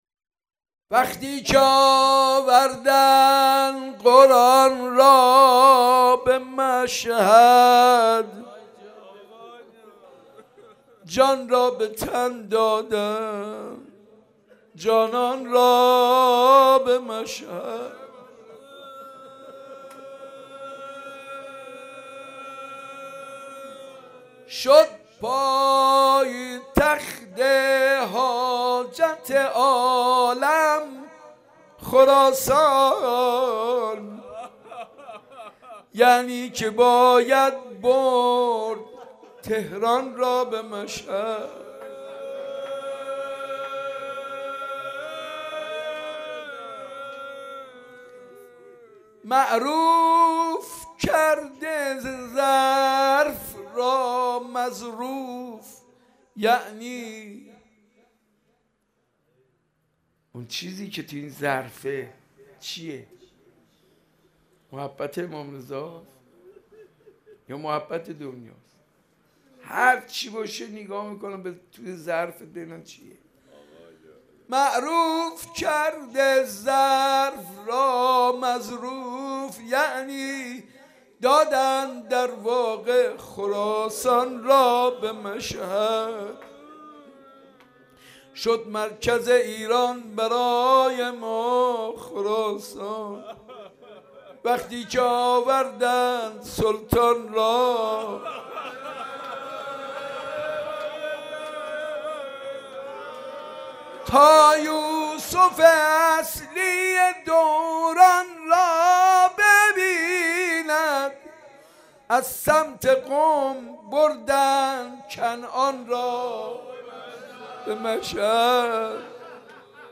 حاج منصور ارضی/شهادت امام موسی کاظم/حسینیه موسی ابن جعفر(ع) مشهد(جدید)